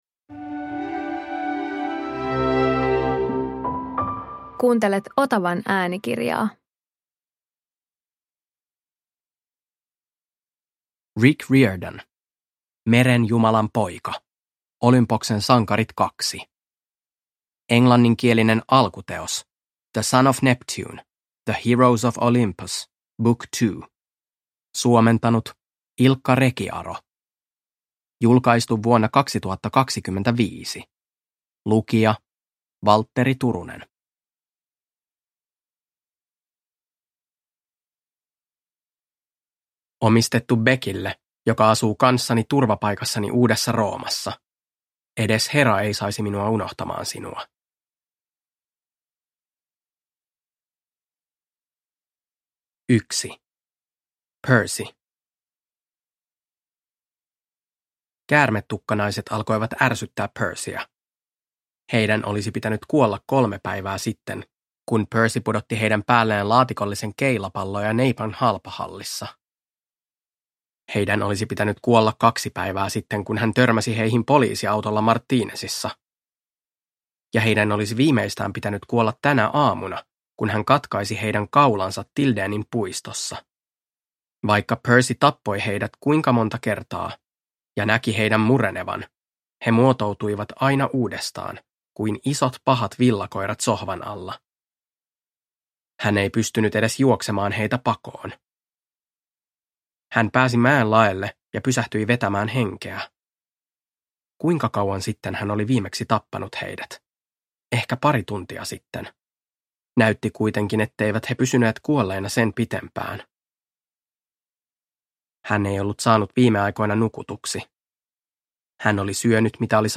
Merenjumalan poika – Ljudbok